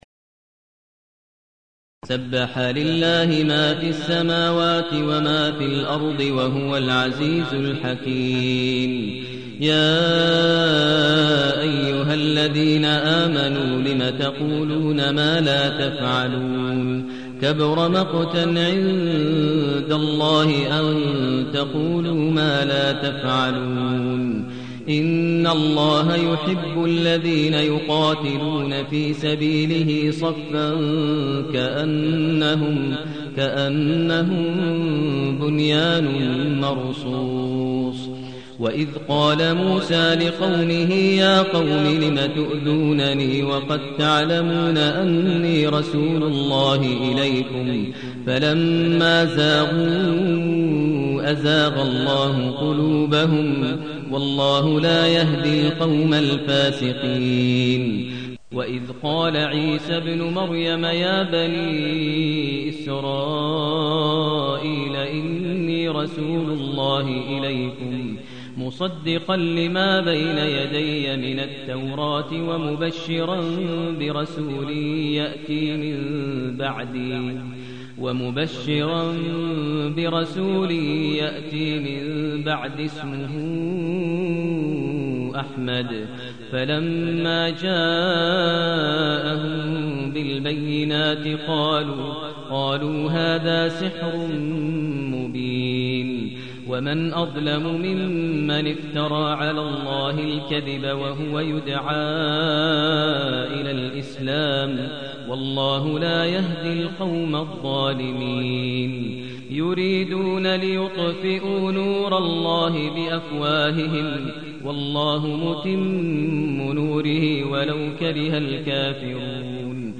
المكان: المسجد النبوي الشيخ: فضيلة الشيخ ماهر المعيقلي فضيلة الشيخ ماهر المعيقلي الصف